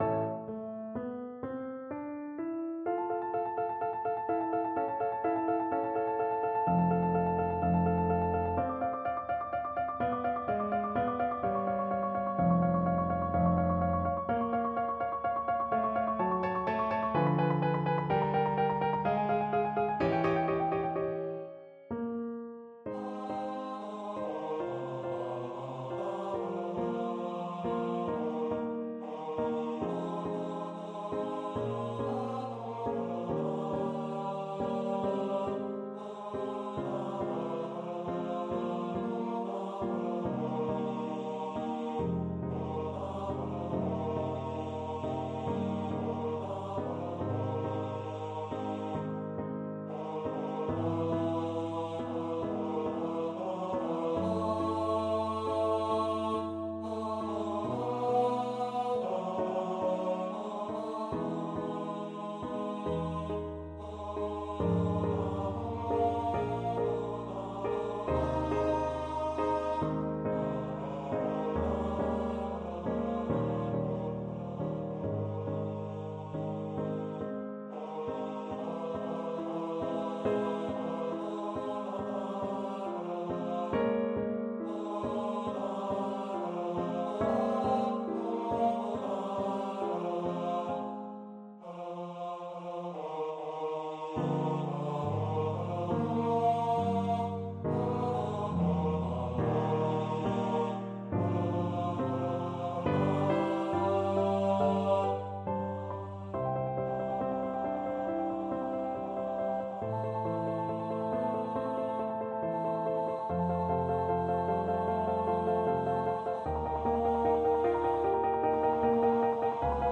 3/4 (View more 3/4 Music)
B3-F5
Andante ( = 63)
Classical (View more Classical Voice Music)